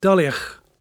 [dULL-uch]